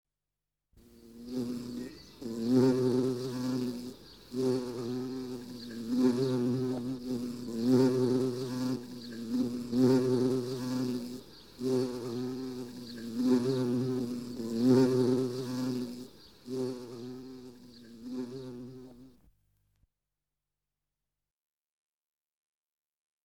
Pianeta Gratis - Audio - Animali
insetti_insect04.mp3